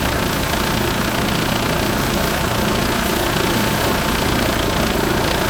main_rotor_idle_power_v2.wav